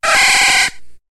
Cri de Lainergie dans Pokémon HOME.